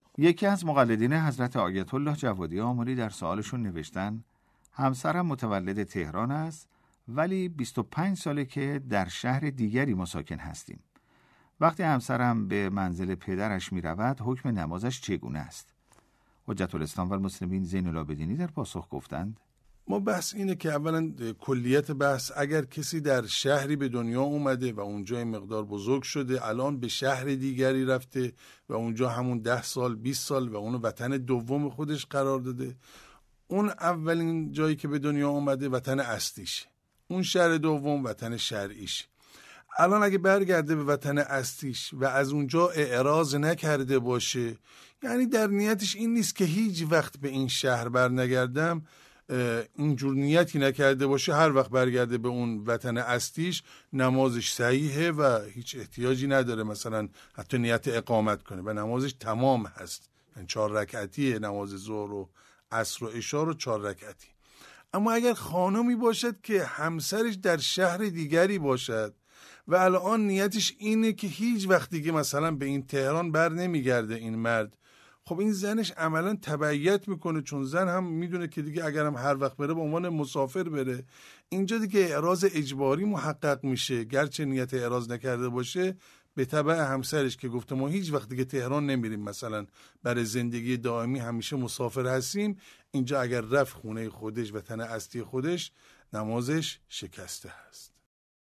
پاسخ نماینده دفتر حضرت آیت الله العظمی جوادی آملی